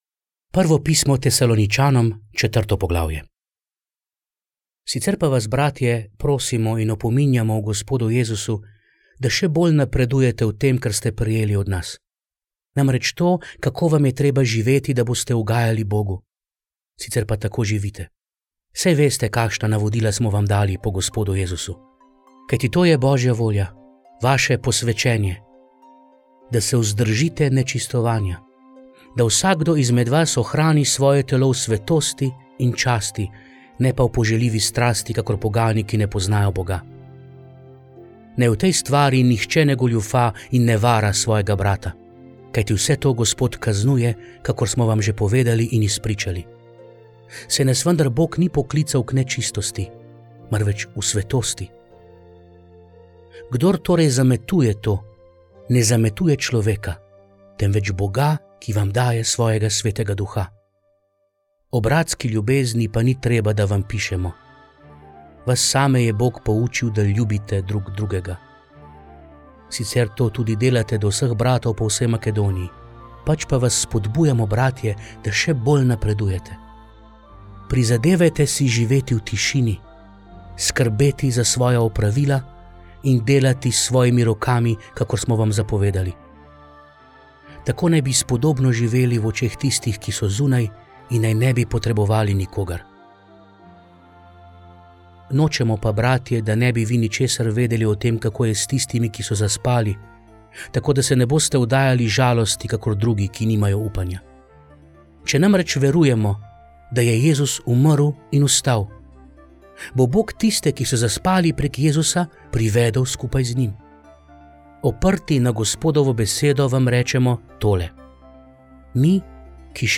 Hvala Bogu so tudi manjkajoče vrstice bile posnete in smo jih v montaži lahko dodali na njihovo mesto.